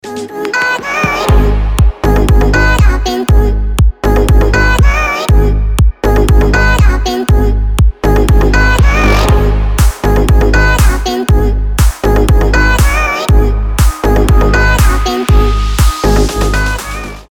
EDM
басы
Brazilian bass
house